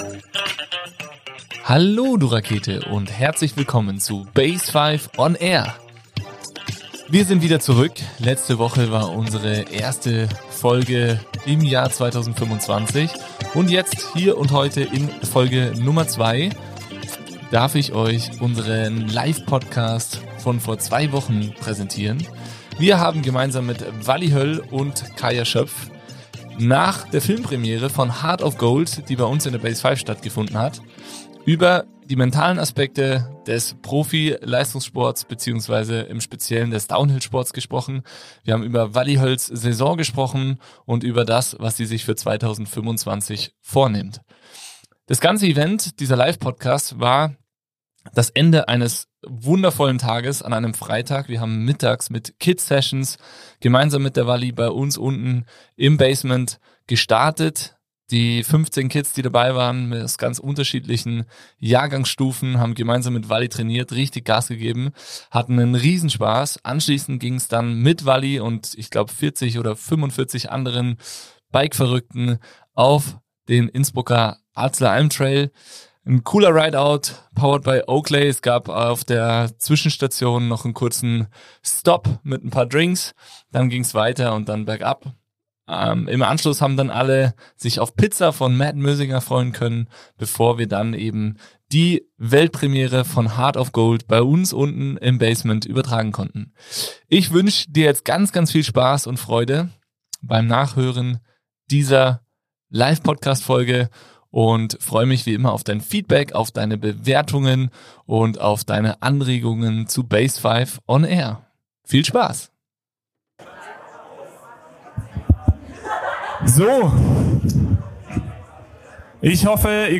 Ein Gespräch über Höhen, Tiefen und die Kraft des richtigen Mindsets. Das und noch viel mehr durften wir bei einem spannenden Live Talk, nach der Weltpremiere von "Heart of Gold" bei uns in der BASEFIVE besprechen.